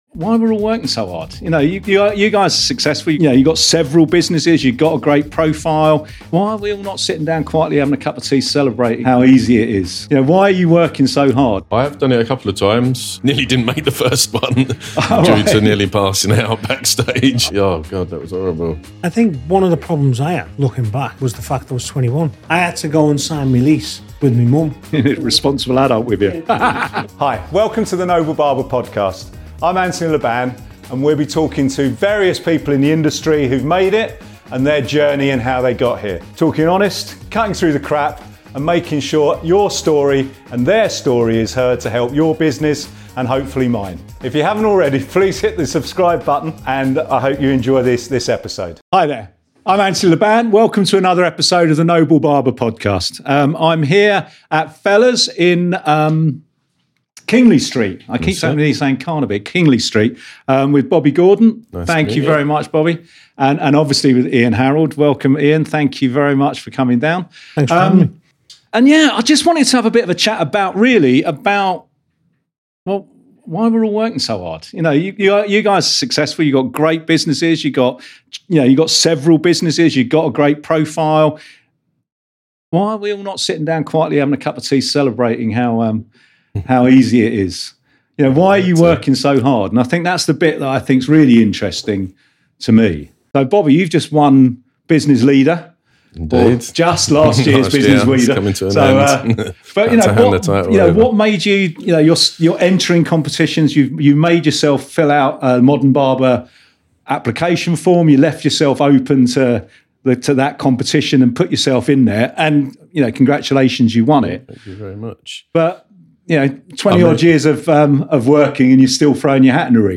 barbering podcast interviews